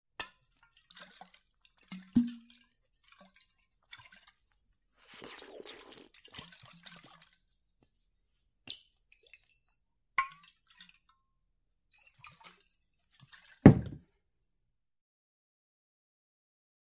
Звуки глотания
Пьяница хлещет виски прямо из горлышка